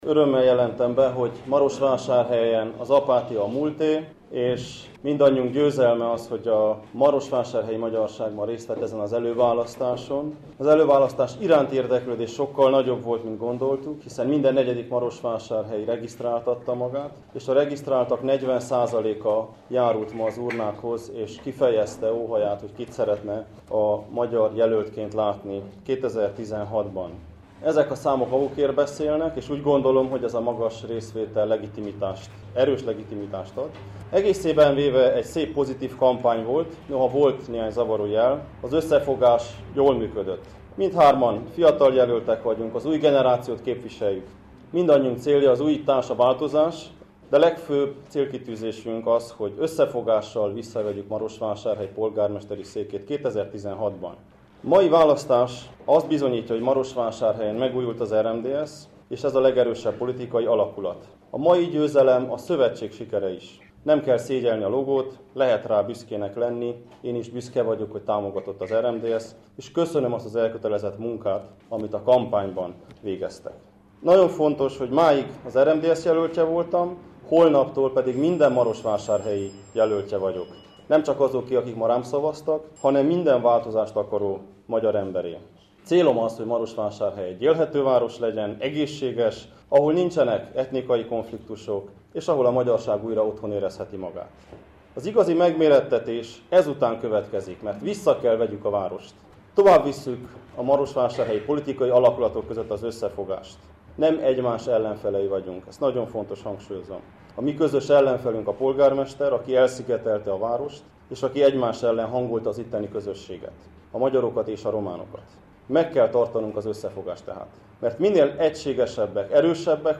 elsőként a nyertes Soós Zoltán beszédéből isdézünk,